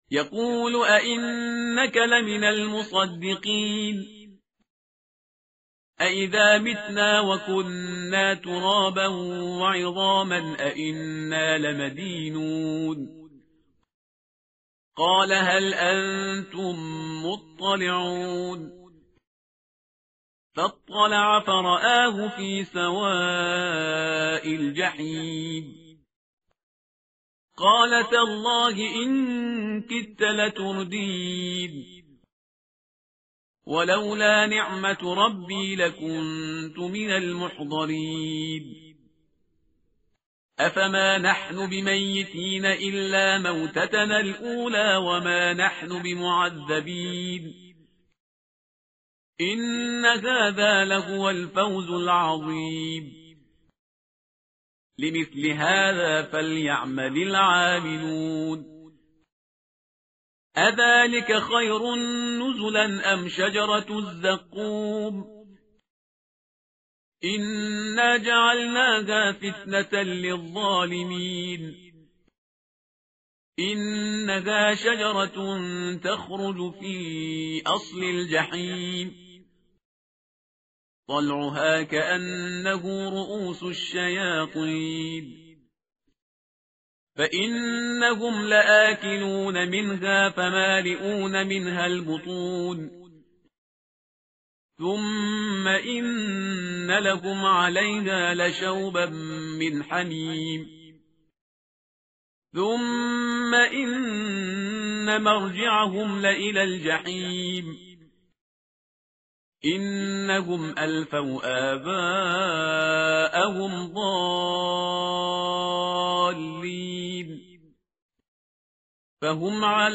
متن قرآن همراه باتلاوت قرآن و ترجمه
tartil_parhizgar_page_448.mp3